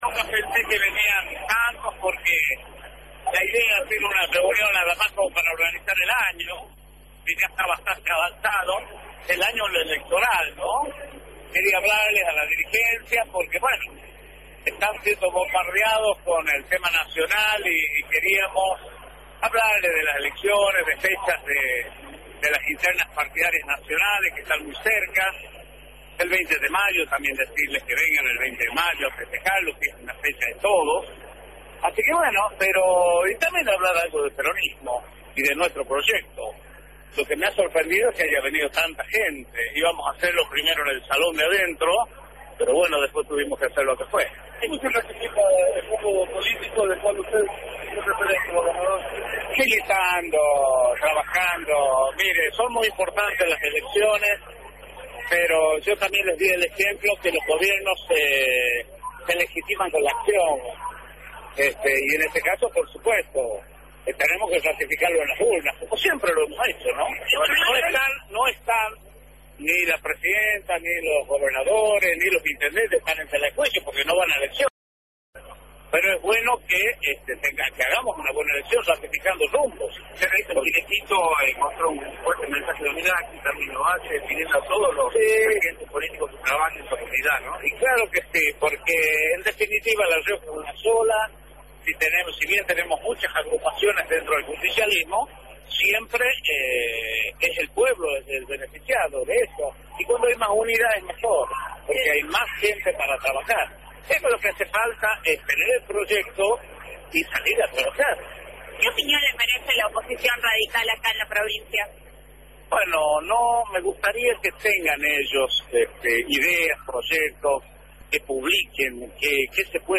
Lo hizo al encabezar un encuentro de dirigentes justicialistas en el Paseo Cultural Castro Barros, en donde en su carácter de presidente del Consejo Provincial del PJ convocó a la dirigencia justicialista a mover toda la maquinaria para enfrentar con éxito los próximos comicios legislativos.